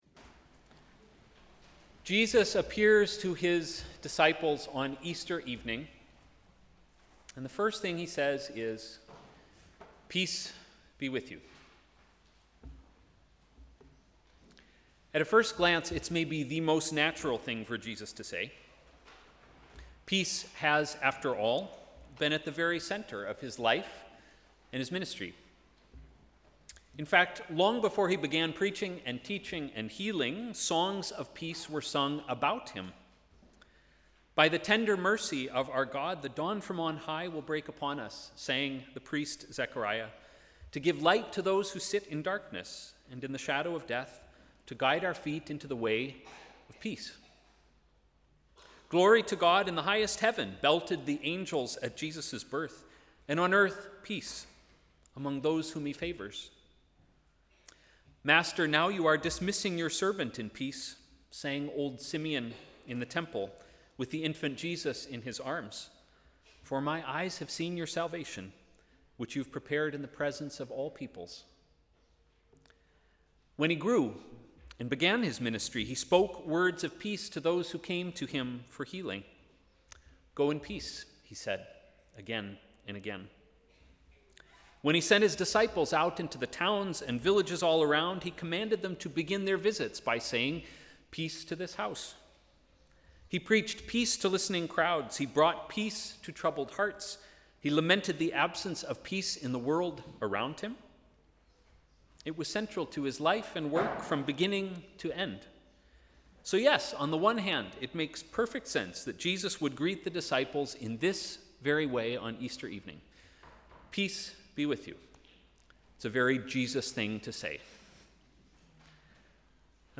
Sermon: ‘Peace in the meantime’